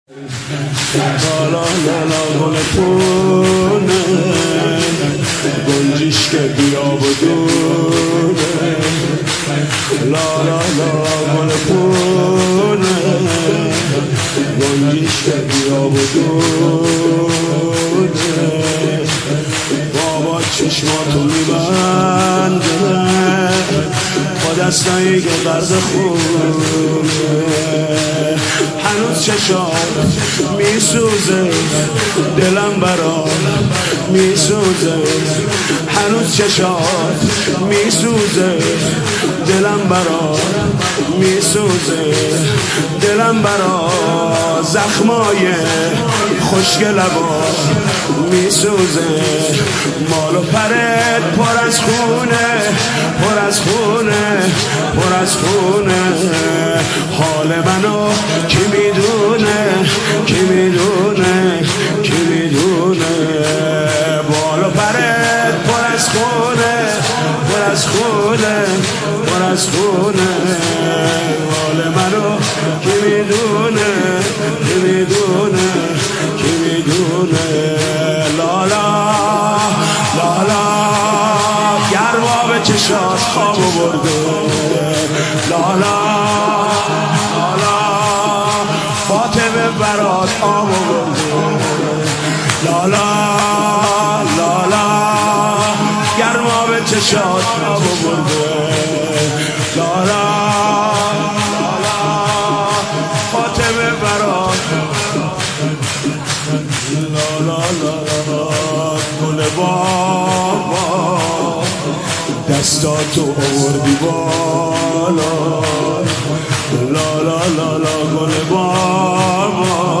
مداحی جدید حاج محمود کريمی شب هفتم محرم97 هيأت راية العباس